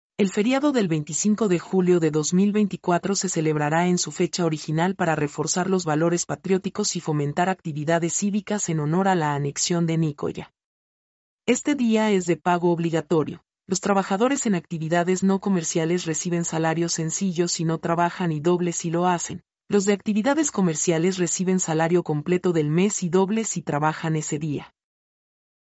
mp3-output-ttsfreedotcom-14-1.mp3